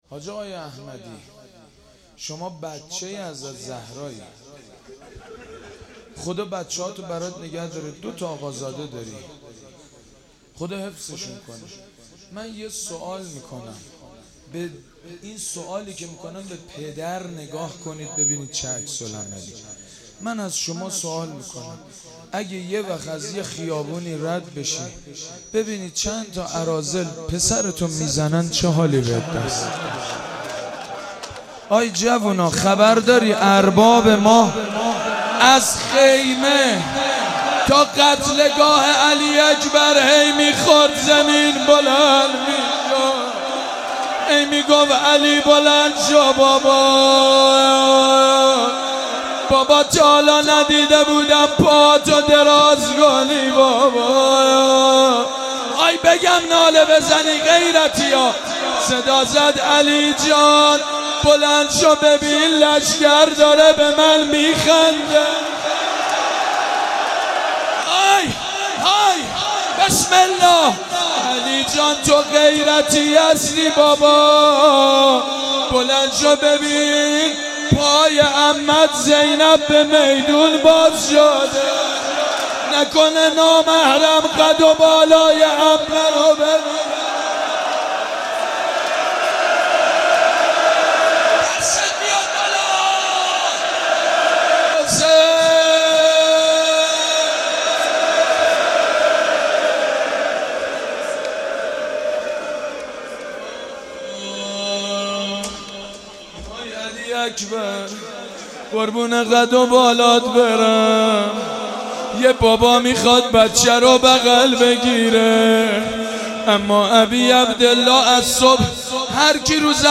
روضه و نوحه شب هشتم محرم سال 92 با نوای حداحان اهل بیت
روضه حضرت علی اکبر (ع) (روضه)